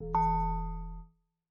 steeltonguedrum_e.ogg